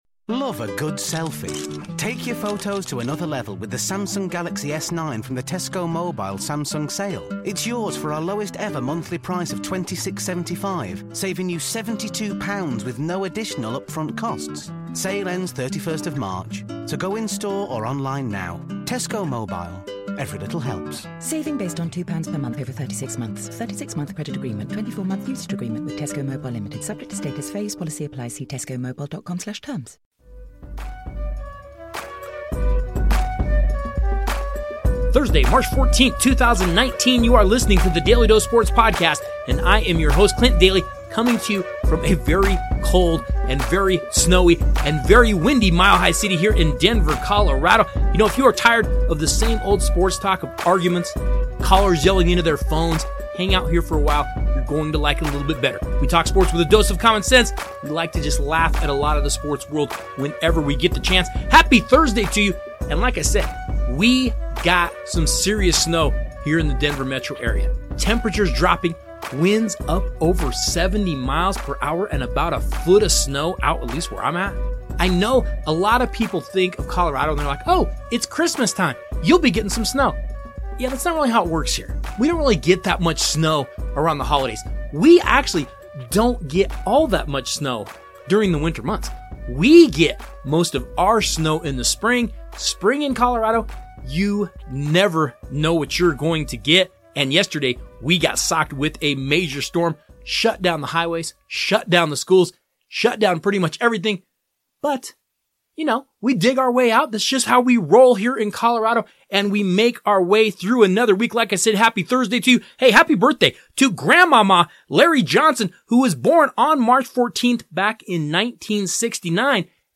interview Part 3